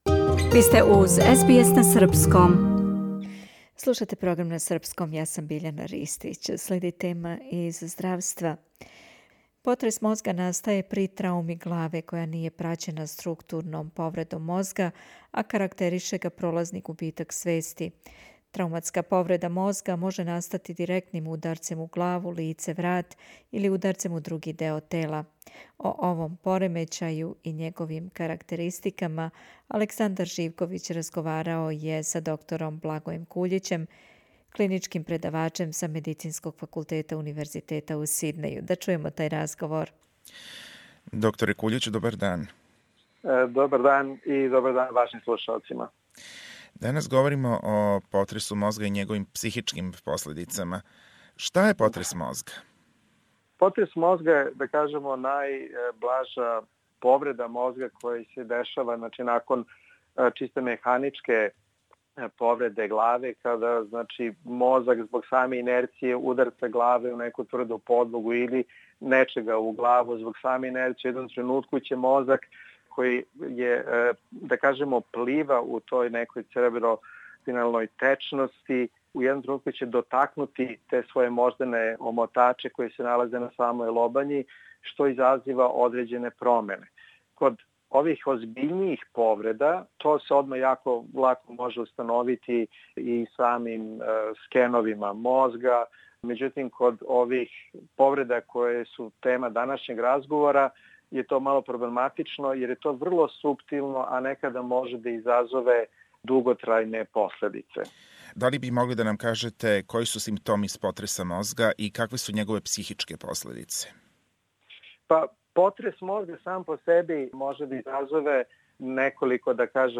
Потрес мозга настаје при трауми главе која није праћена структурном повредом мозга, а карактерише га пролазни губитак свести. Трауматска повреда мозга може настати директним ударцем у главу, лице, врат или ударцем у други део тела. О овом поремећају и његовим карактеристикама разговарамо са психијатром